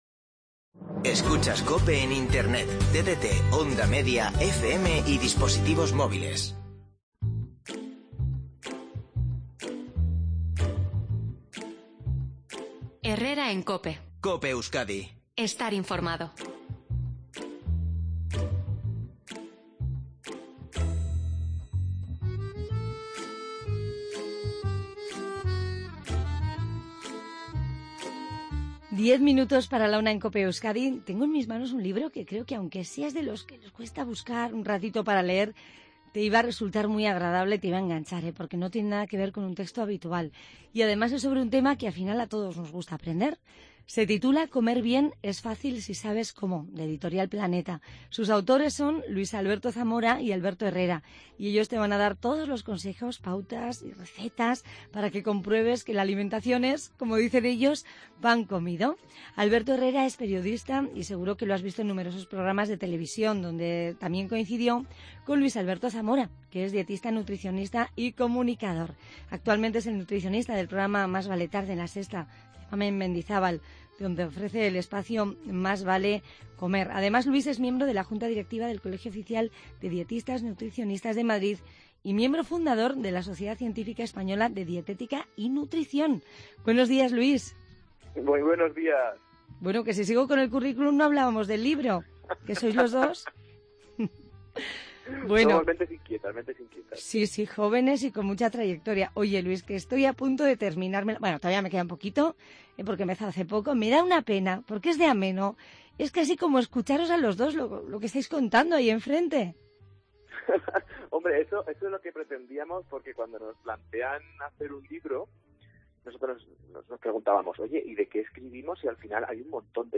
No te pierdas la entrevista donde cuenta cómo es el libro que te va a ayudar a desterrar bulos y mitos erróneos y a hacer la compra de manera sencilla para estar sano y disfrutar alimentándote.